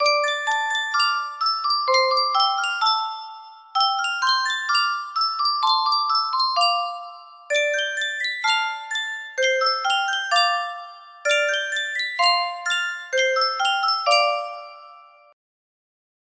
Full range 60